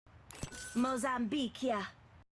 lifeline-saying-mozambique-here.mp3